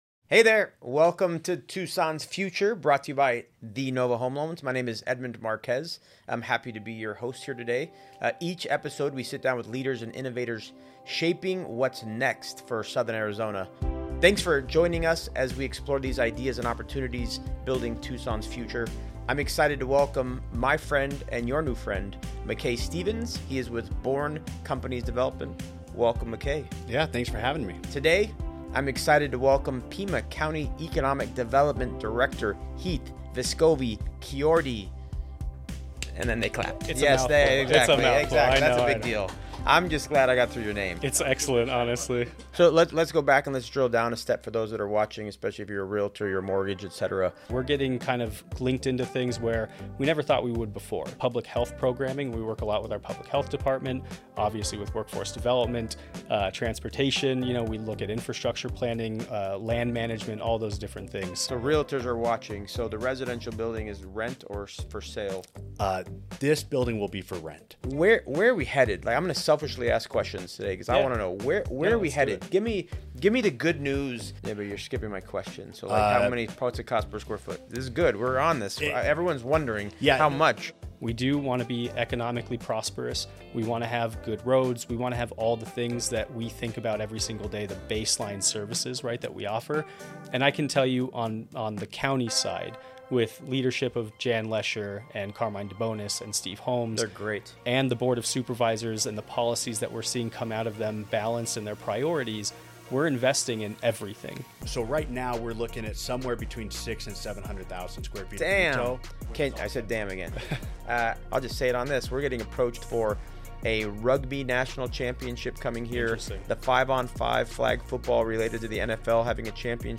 Each episode dives into conversations with regional leaders, innovators, and changemakers driving growth, development, and opportunity across our community.